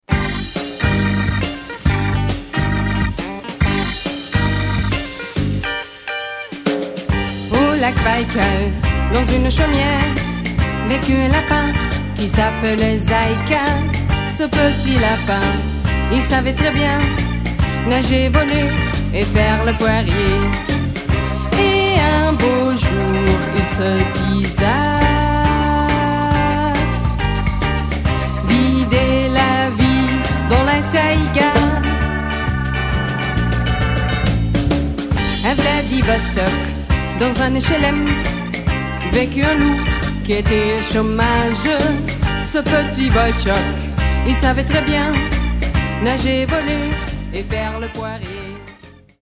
französischen Sixties-Beat, charmante Pop-Songs